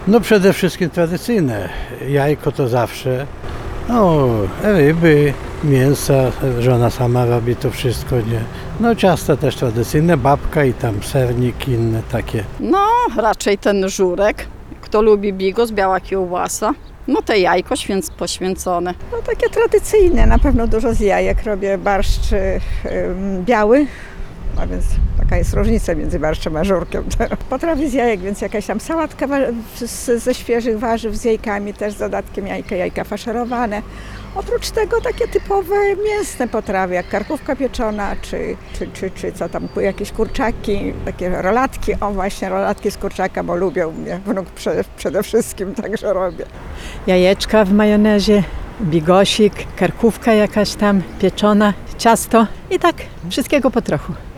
Na to pytanie odpowiadali przechodnie zapytani na ulicach Suwałk. W ich wypowiedziach najczęściej pojawiały się trzy symbole świątecznego menu: aromatyczny bigos, tradycyjny żurek oraz jajka, nieodłączny element wielkanocnego śniadania.